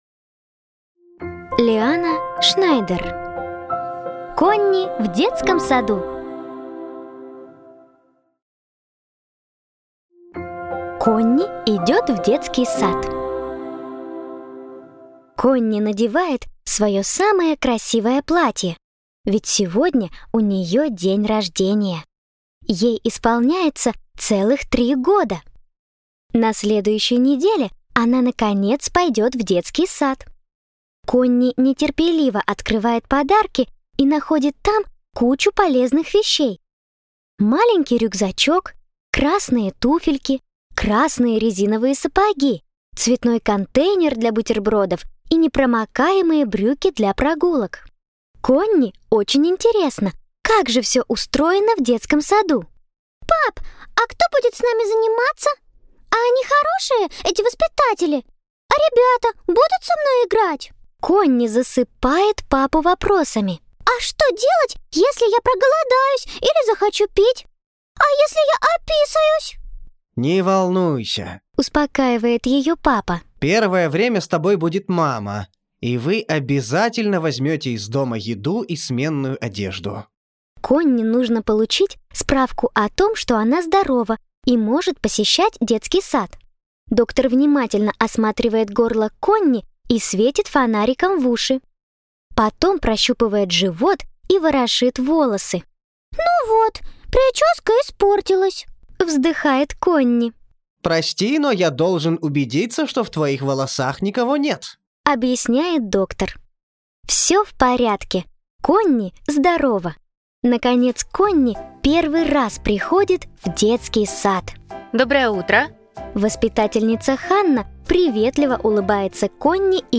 Аудиокнига Конни в детском саду | Библиотека аудиокниг